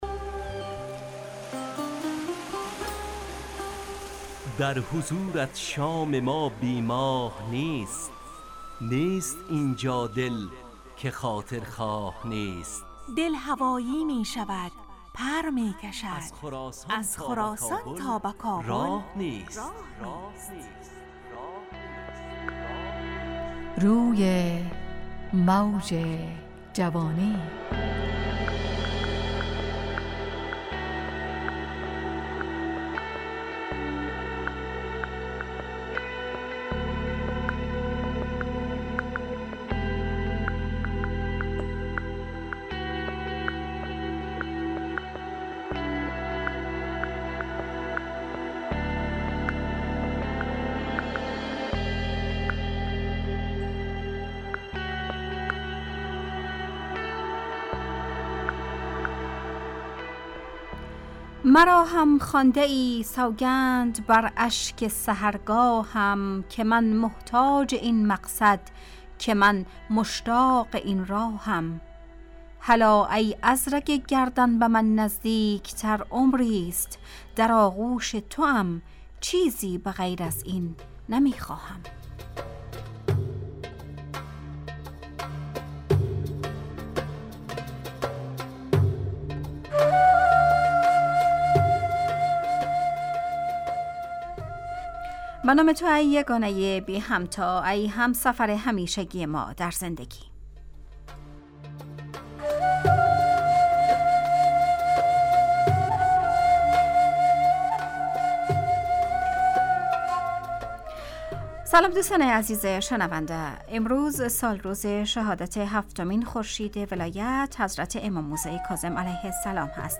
روی موج جوانی، برنامه شادو عصرانه رادیودری.
همراه با ترانه و موسیقی مدت برنامه 55 دقیقه . بحث محوری این هفته (هنر) تهیه کننده